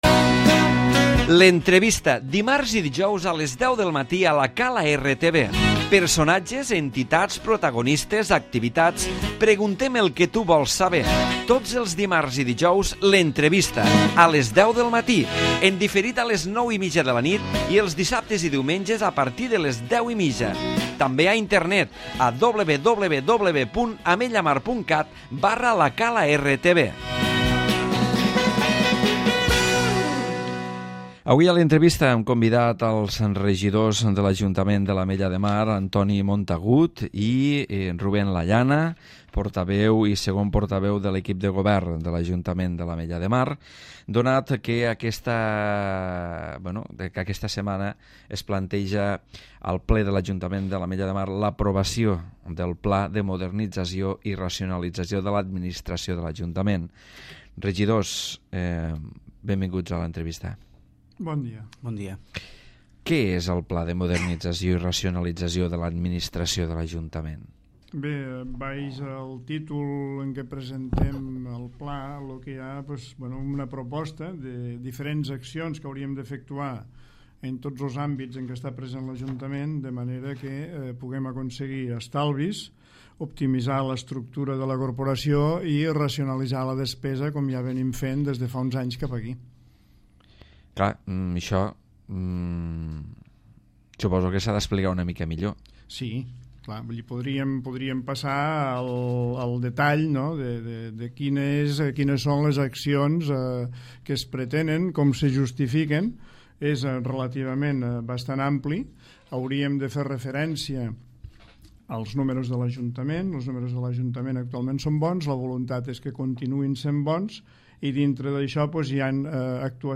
L'Entrevista
Antoni Montagut, Regidor de Finances i portaveu de l'equip de govern, i Rubén Lallana, segon portaveu expliquen els trets més bàsics del Pla de Modernització i Racionalització de l'administració que l'equip de govern vol aplicar a l'Ajuntament.